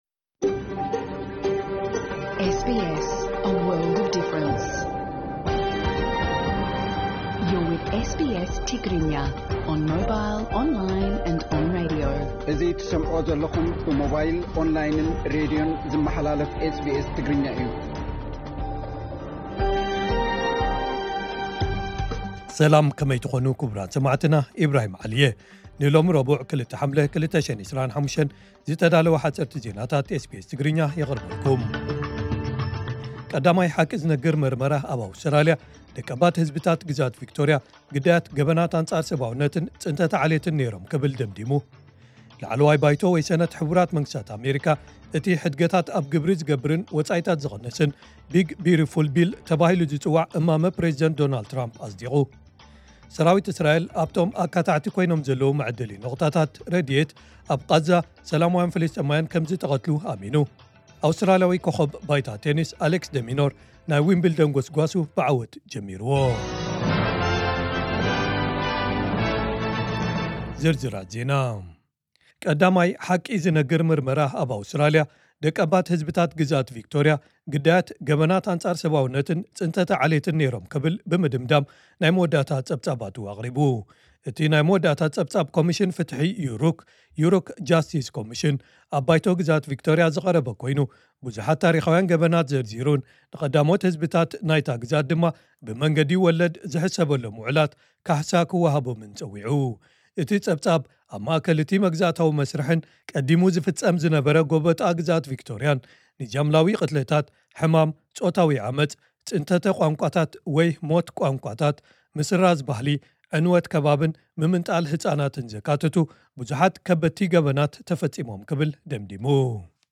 ሓጸርቲ ዜናታት ኤስ ቢ ኤስ ትግርኛ (02 ሓምለ 2025)